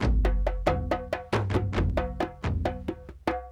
38 Kendong 01.wav